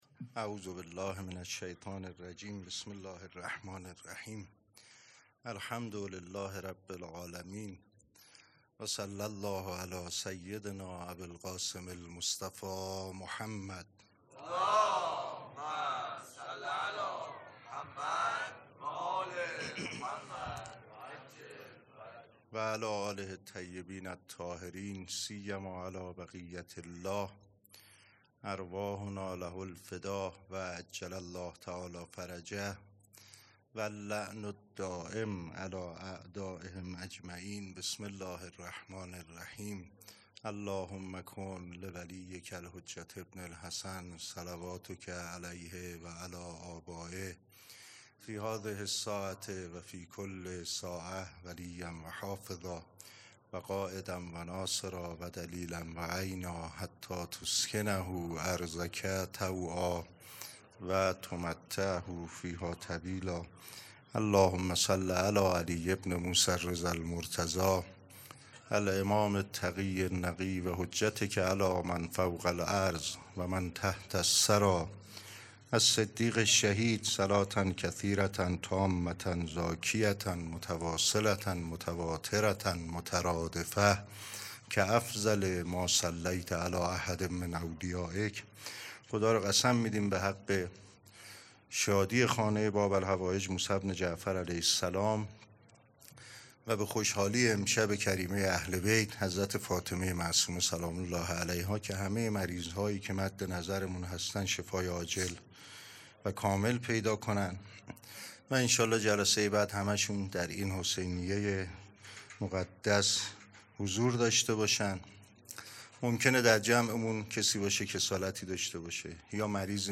سخنرانی
مراسم جشن ولادت امام رضا علیه‌السّلام پنجشنبه ۱۸ اردیبهشت ماه ۱۴۰۴ | ۱۰ ذی‌القعده ۱۴۴۶ حسینیه ریحانه الحسین سلام الله علیها